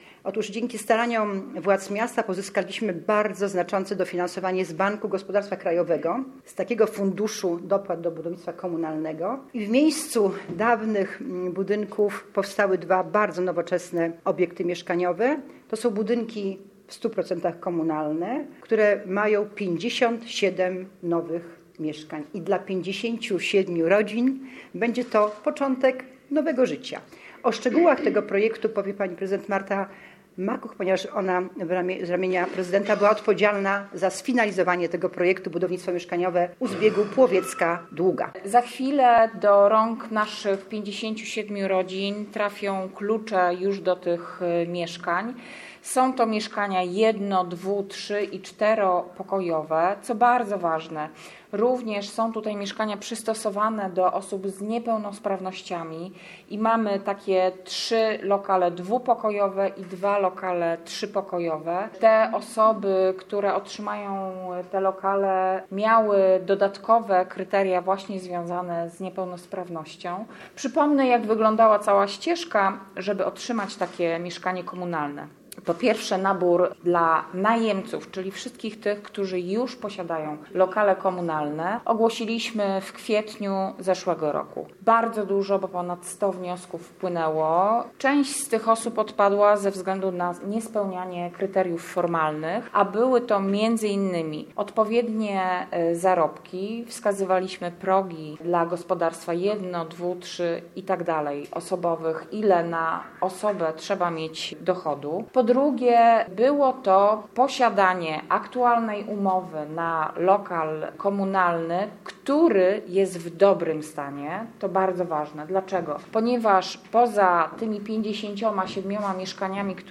– Stanęły w miejscu wyburzonych kamienic, które nie spełniały dzisiejszych standardów – mówi prezydent Słupska Krystyna Danilecka – Wojewódzka.